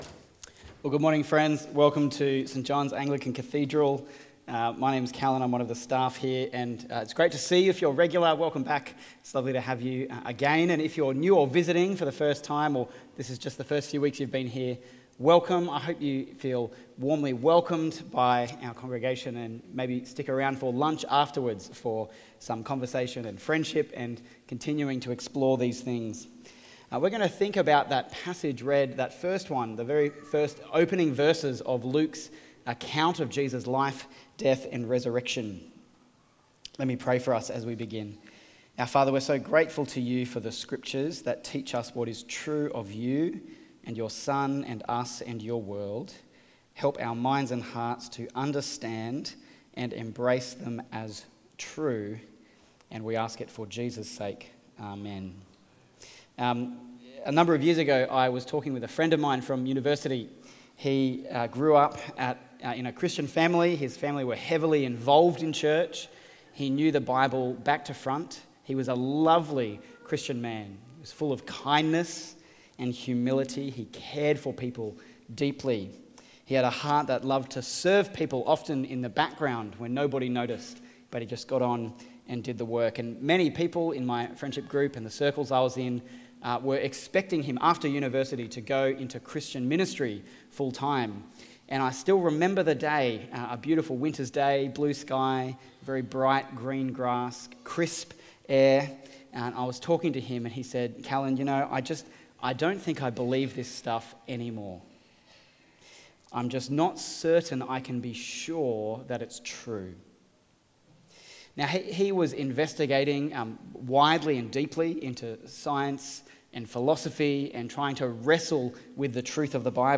Sermons | St Johns Anglican Cathedral Parramatta
Watch the full service on YouTube or listen to the sermon audio only.